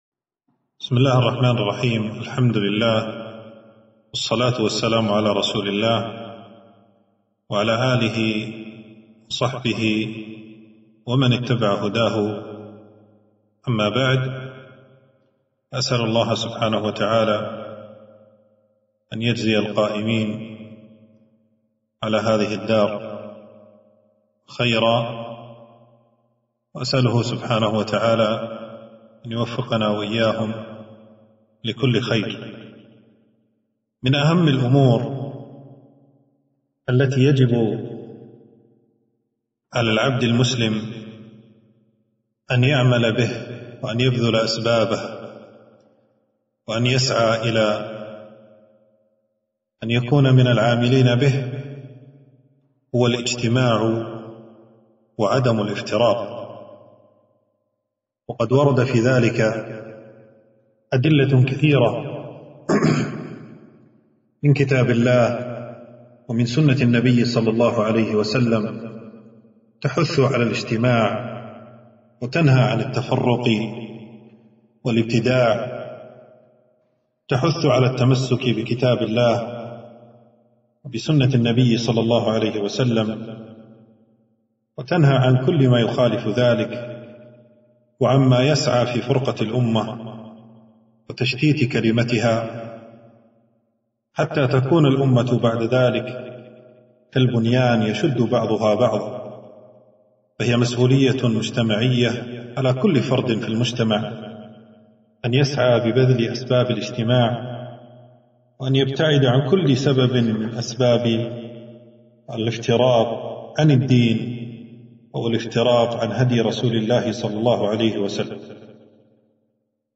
محاضرة - وَاعْتَصِمُوا بِحَبْلِ اللَّهِ جَمِيعًا وَلا تَفَرَّقُوا